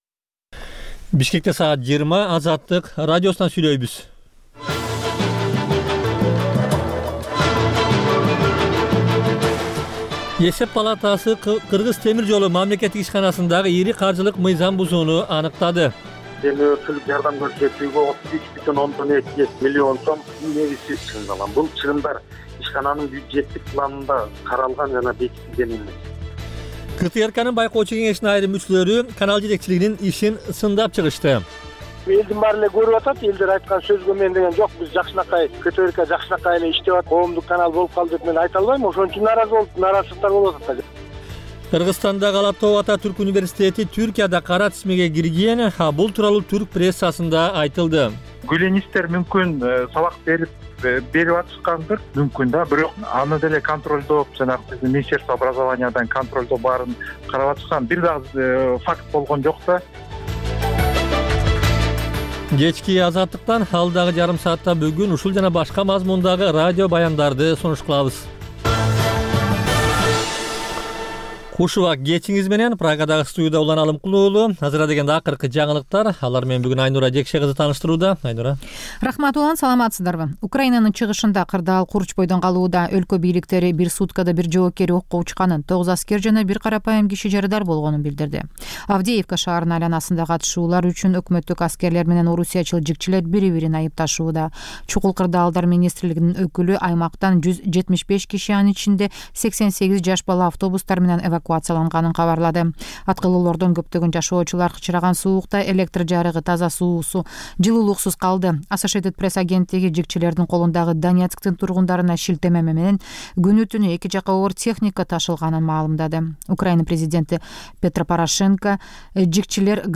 Бул үналгы берүү ар күнү Бишкек убакыты боюнча саат 20:00дан 21:00гө чейин обого түз чыгат.